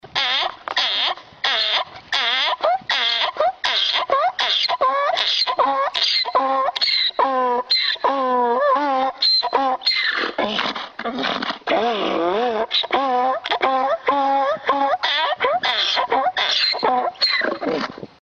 Звуки ослов
Ишак ревёт